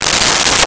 Metal Zipper 05
Metal Zipper 05.wav